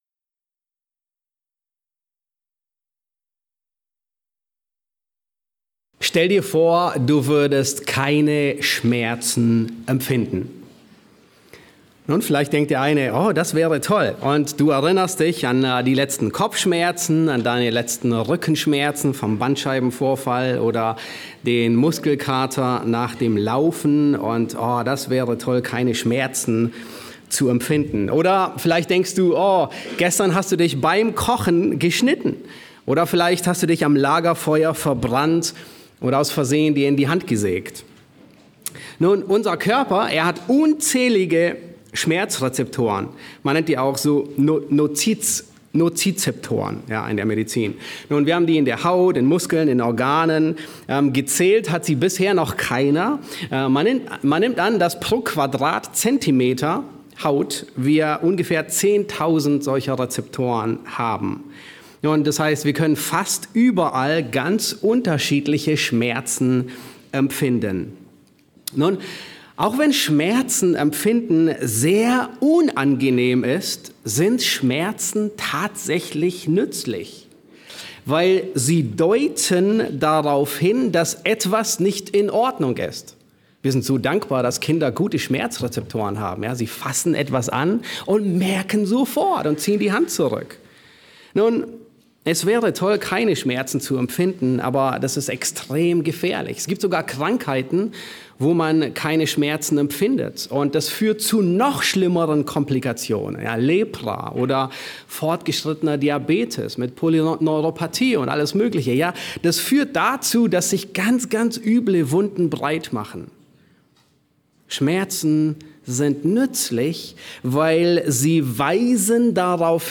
Hier findest du die Predigten der evangelischen Freikirche Leuchtturm e.V. Wir sind eine junge und dynamische Freikirche in Berlin, mit einer großen Leidenschaft die Wahrheiten der Bibel zu lernen und zu leben.